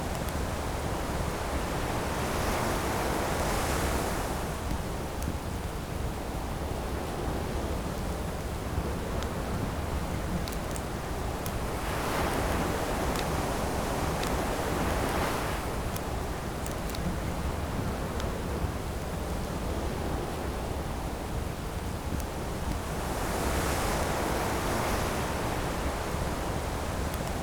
ambient_rain.wav